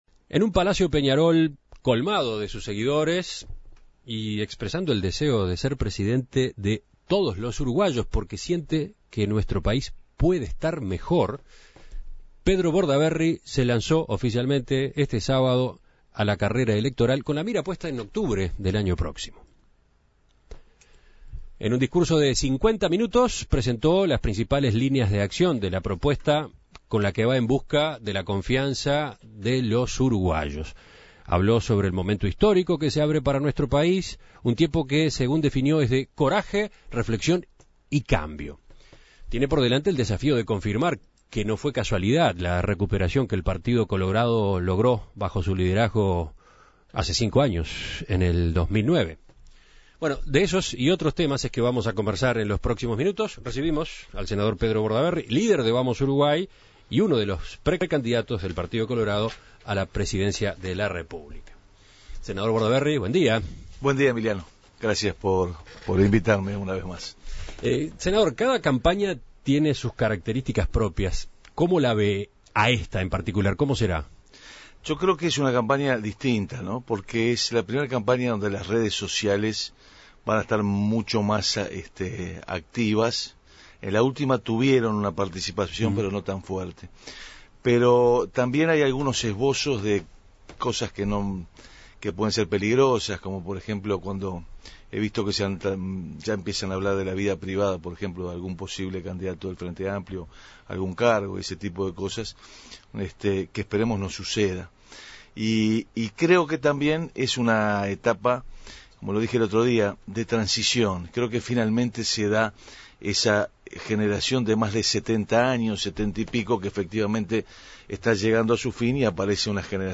Para conocer más sobre esta candidatura que se acaba de lanzar, En Perspectiva entrevistó al precandidato a la Presidencia por Vamos Uruguay (PC). Bordaberry dijo que Tabaré Vázquez actuó con mucha "soberbia y arrogancia" en su discurso de la semana pasada, y que ya se da como ganador.